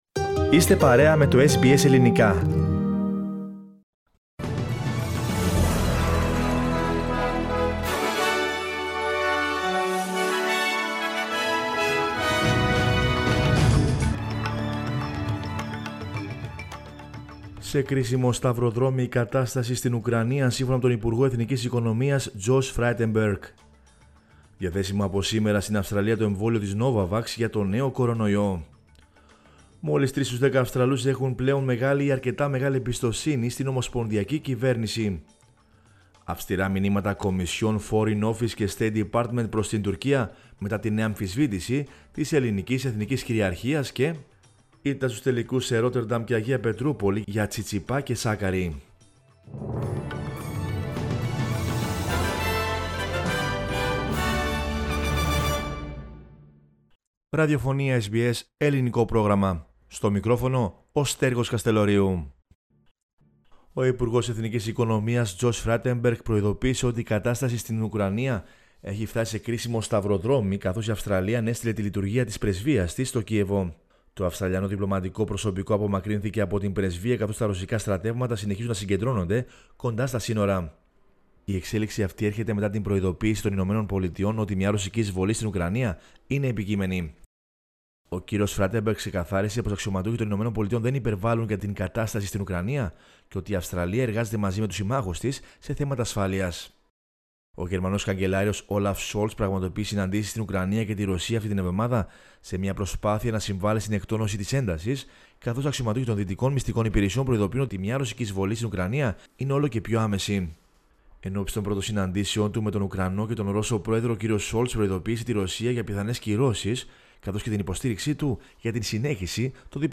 News in Greek from Australia, Greece, Cyprus and the world is the news bulletin of Monday 14 February 2022.